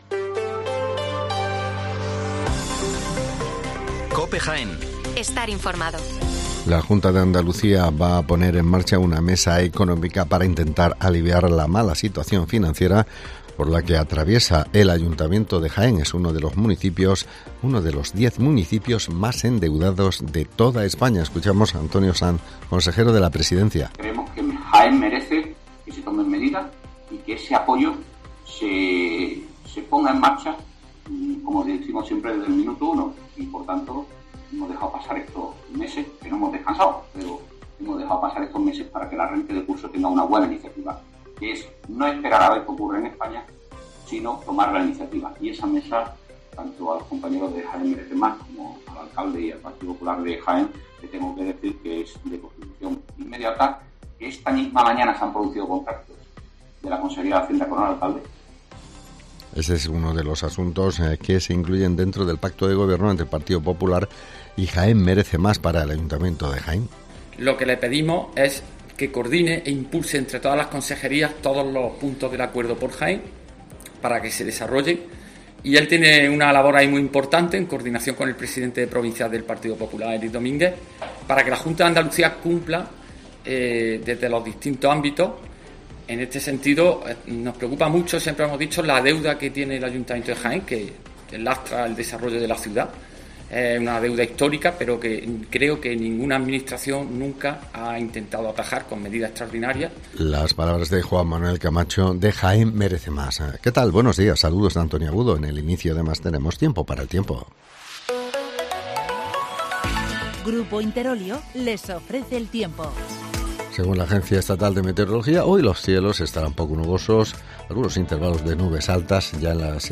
Las noticias locales de las 8'24 del 7 de septiembre de 2023